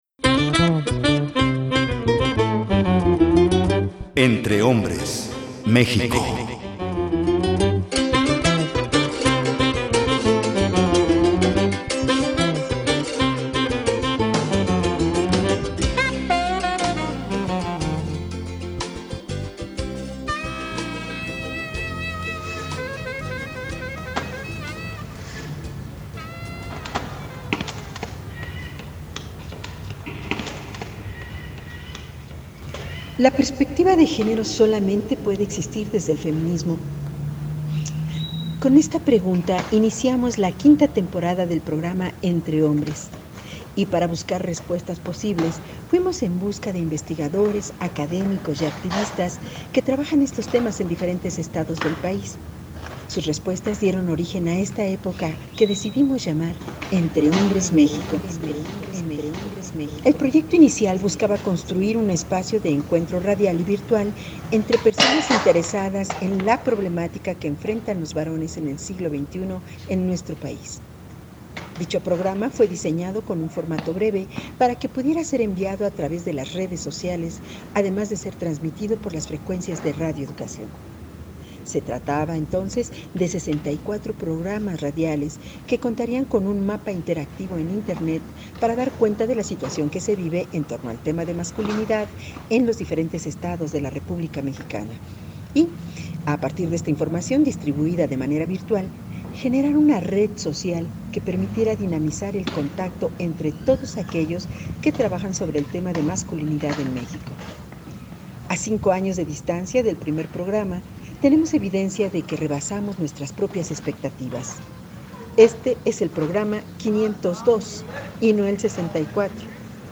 Las voces que escucharán en este programa fueron grabadas con teléfonos celulares con los cuales podemos obtener grabaciones de todo el mundo en cuestión de segundos.